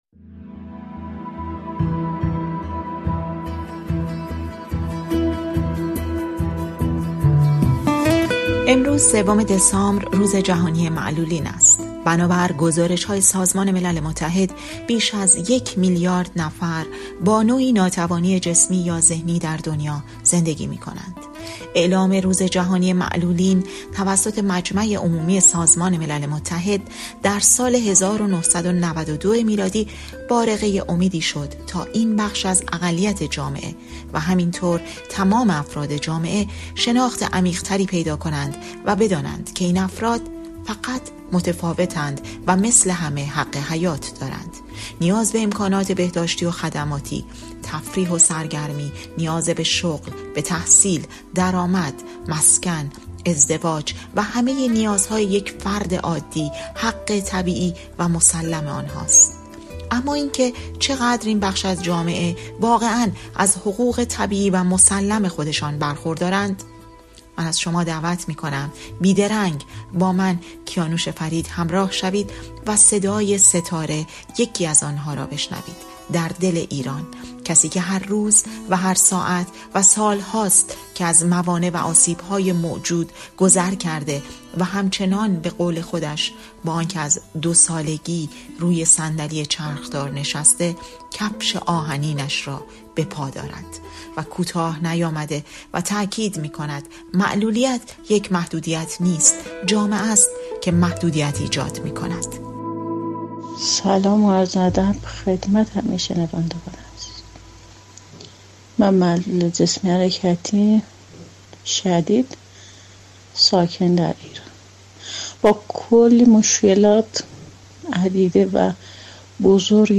در گفت‌وگویی ویژه با رادیوفردا